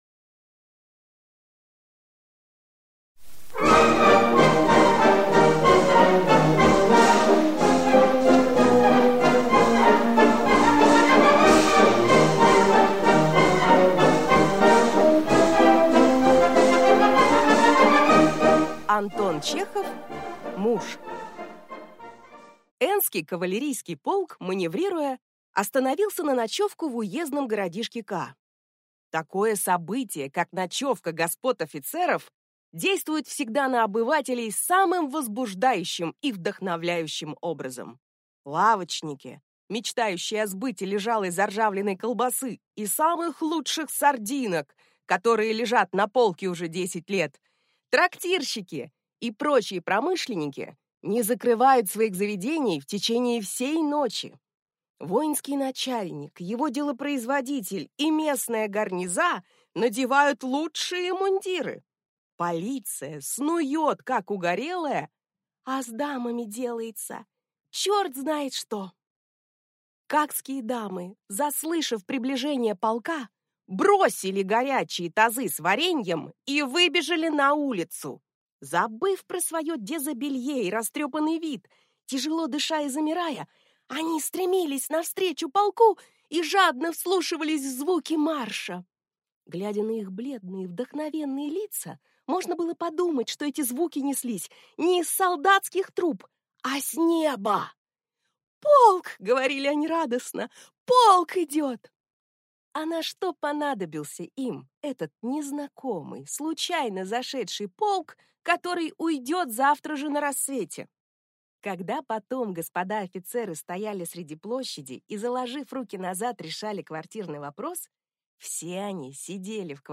Аудиокнига Муж | Библиотека аудиокниг